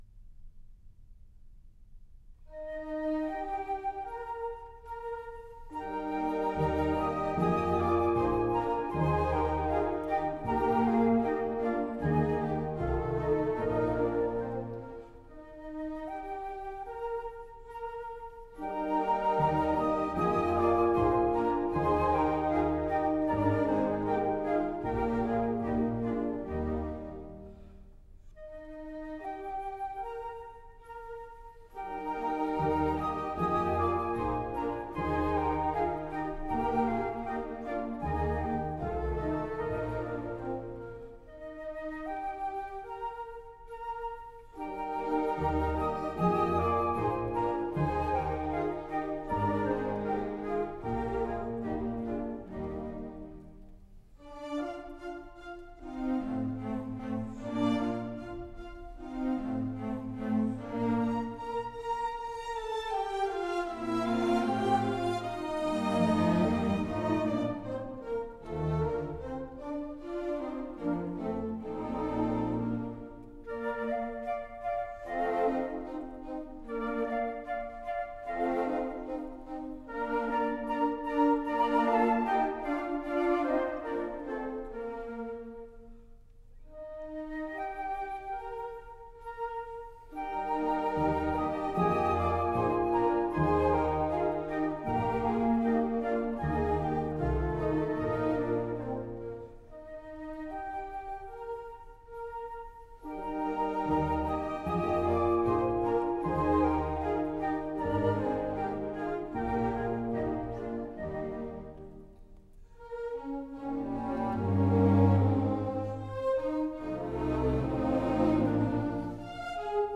Andantino grazioso